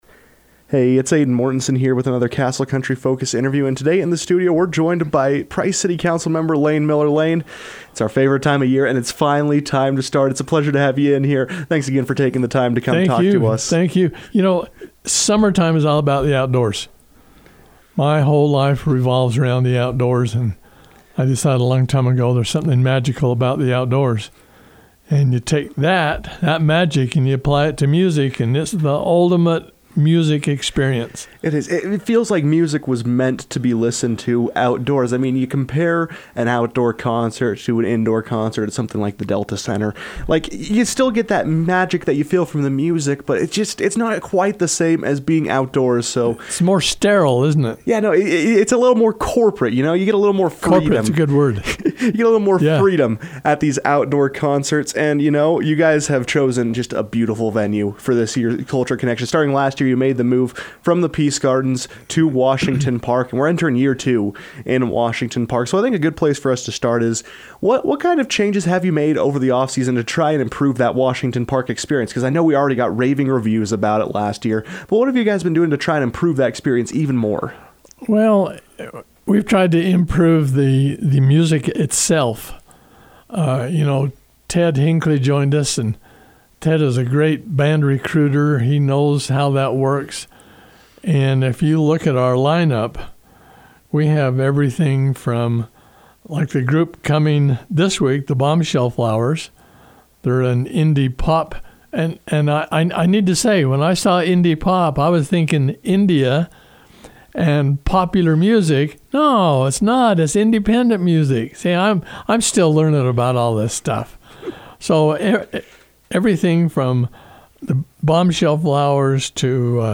Price City Councilmember Layne Miller joined the KOAL newsroom to preview the event and discuss the impact Culture Connection is having on the city.